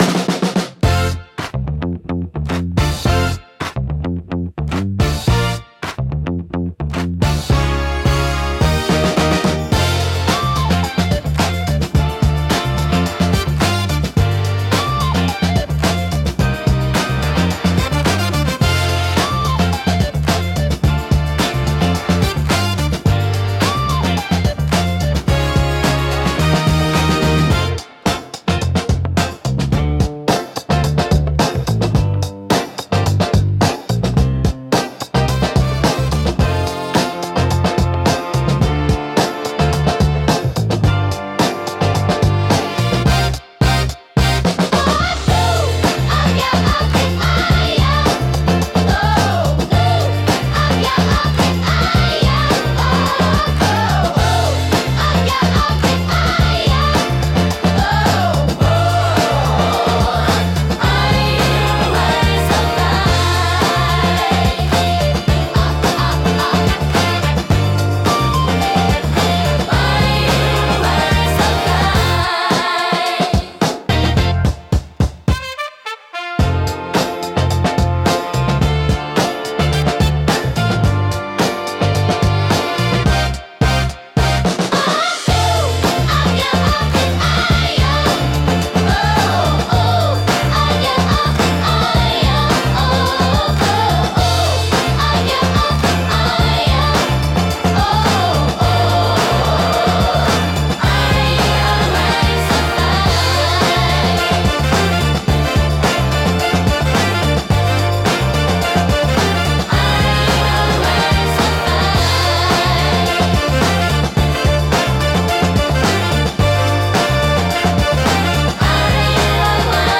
心に残るハーモニーと温かさが魅力のジャンルです。